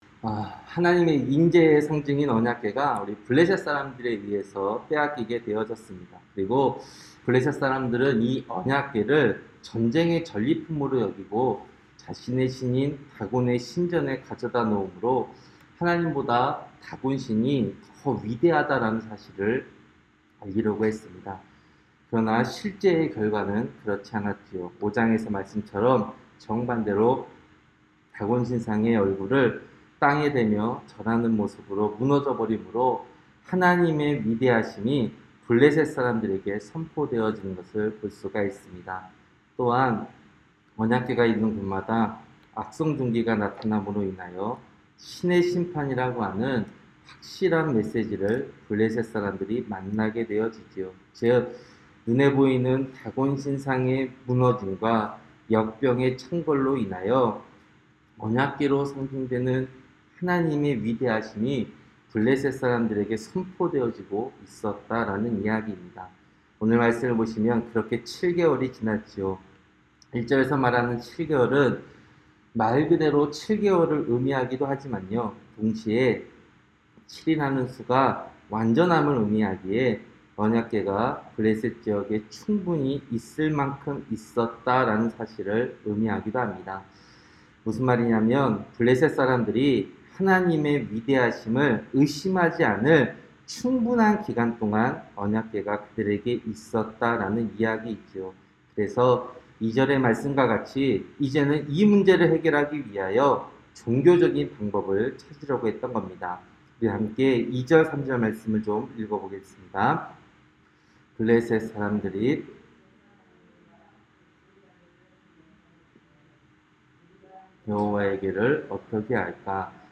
새벽설교-사무엘상 6장